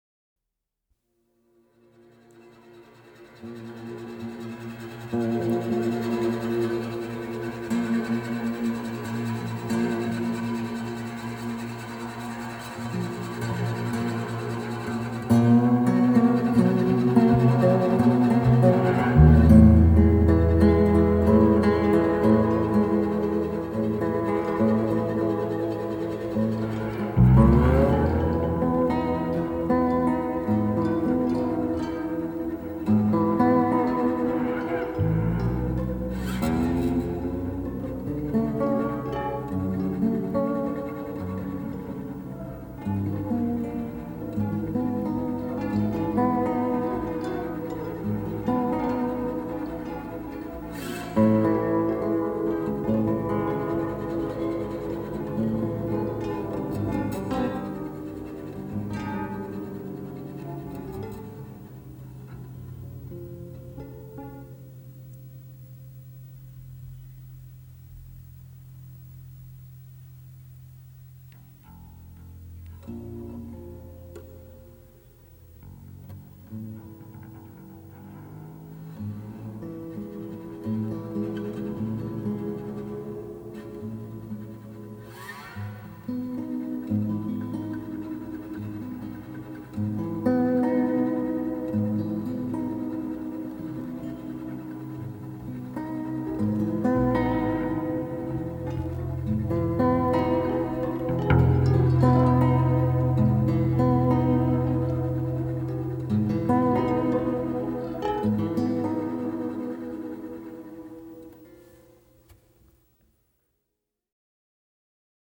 每每听到那空灵、孤独的滑棒吉他弦音，听者的思绪会飘到美国西部辽阔而荒芜的沙漠，最后消失在低垂的夜幕中。
音樂類型：電影原聲帶(電影配樂)